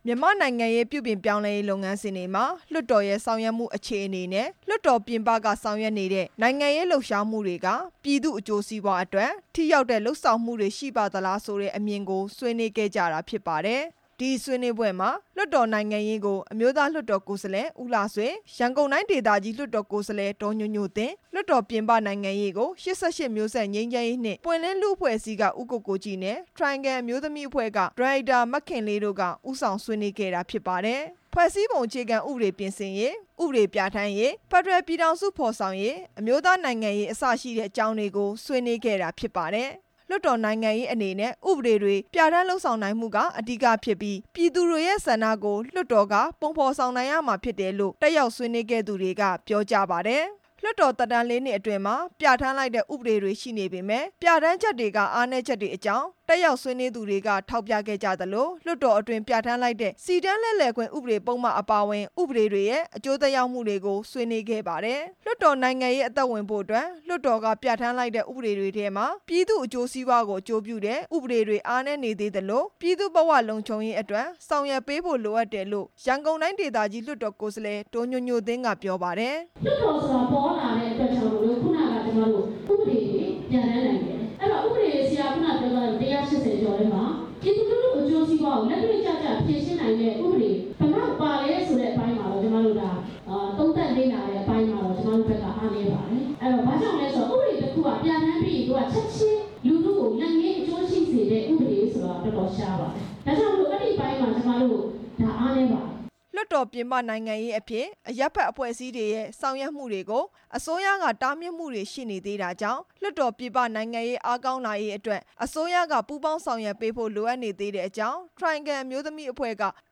လွှတ်တော်နိုင်ငံရေးနဲ့ လွှတ်တော်ပြင်ပနိုင်ငံရေး ဘယ်လှုပ်ရှားမှုက ပြည်သူ့အကျိုးစီးပွားကို ပိုမိုဖော်ဆောင်နိုင် သလဲ ဆိုတဲ့ခေါင်းစဉ်နဲ့ ဆွေးနွေးပွဲတစ်ခုကို ရန်ကုန်မြို့ တော်ဝင်နှင်းဆီခန်းမမှာ လွှတ်တော်ကိုယ်စားလှယ်တွေ ၊ နိုင်ငံရေးတက်ကြွလှုပ်ရှားသူတွေရဲ့ ဒီနေ့ ကျင်းပခဲ့ပါတယ်။